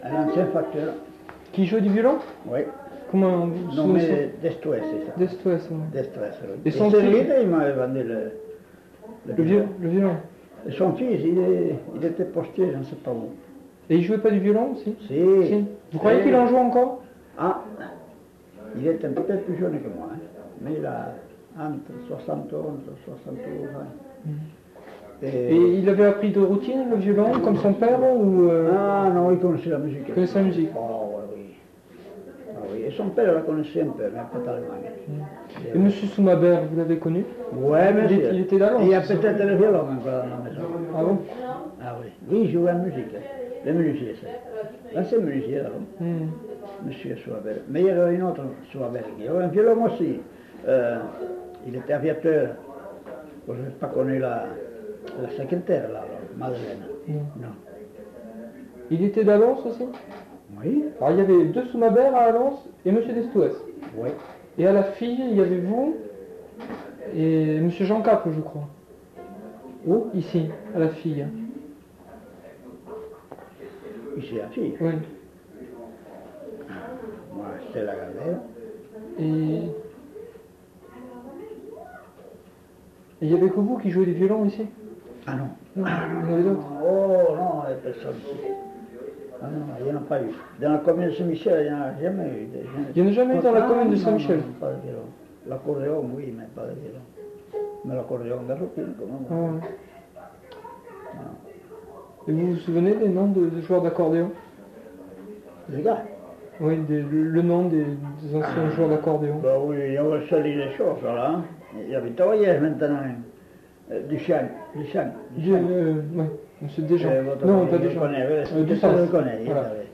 Le violon dans la musique traditionnelle dans les Landes girondines du Lot-et-Garonne Association pour la culture populaire en Agenais
enquêtes sonores